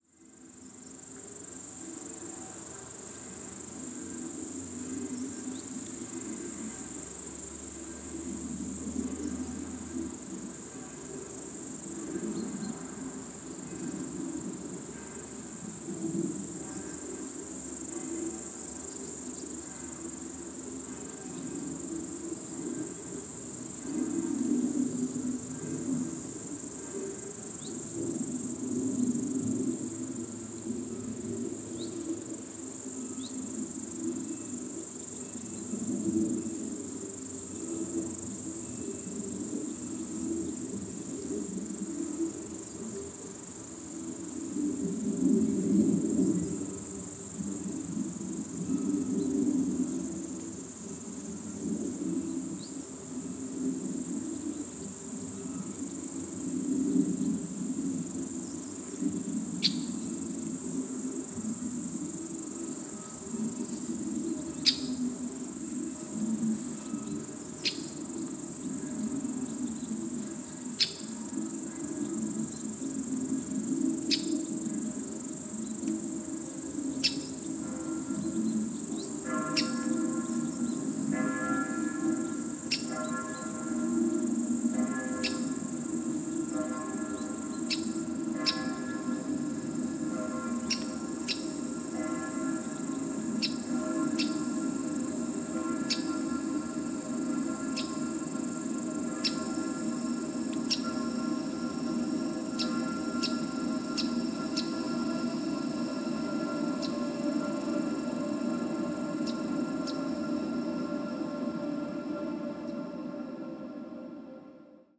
A musical physiognomy of the soundscape
Mahler (in/a) Cage is a field recording work, a process of possible reconstruction of a hypothetical and natural soundscape, within Mahler’s music or rather his musical imaginary following some archetypal signals (e.g. cowbells, birdsongs).
Recordings made in Dobbiaco/Toblach (Bozen)
Unfolding across seven parts, the seventy-four-minute soundscape emerges, appropriately enough, from silence at dawn with rustlings of the land, wind, and bird noises gradually fleshing out the sound field.